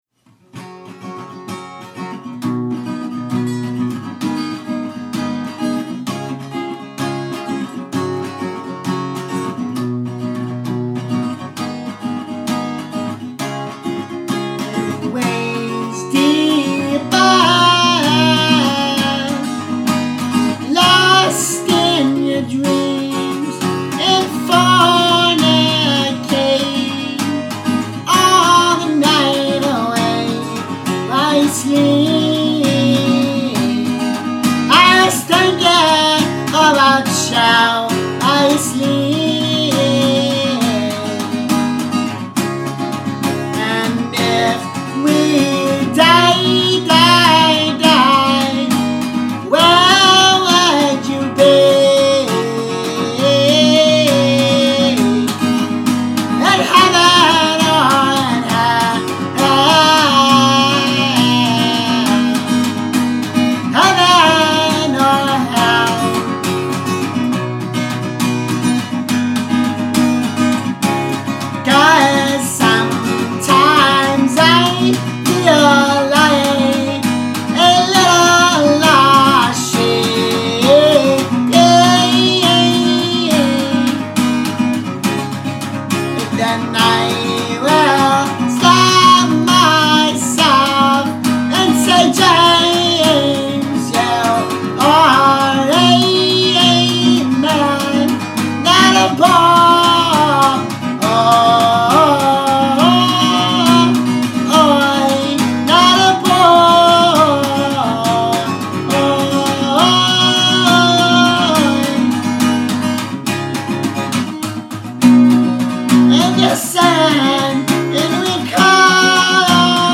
dance/electronic
Punk
Experimental
Indy